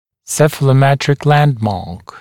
[ˌsefələˈmetrɪk ‘lændmɑːk][ˌсэфэлэˈмэтрик ‘лэндма:к]цефалометрическая точка, ориентир